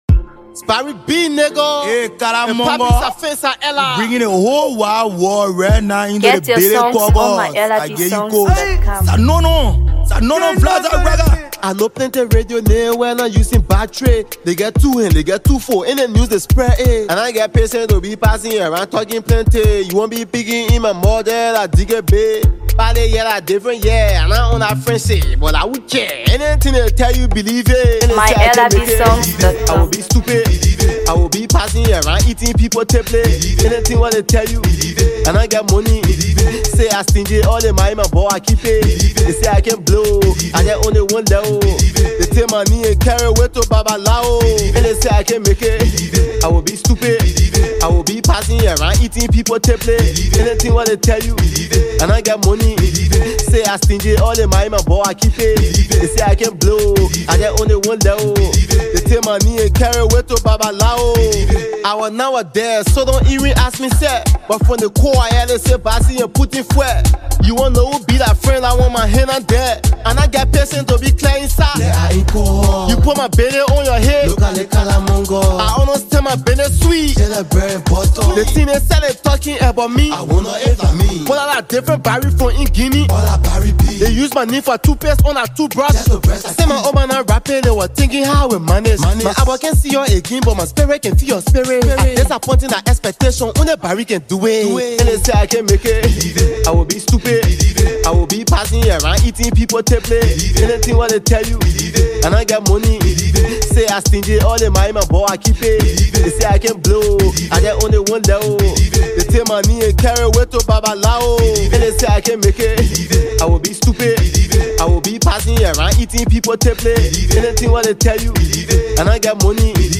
hardcore rapper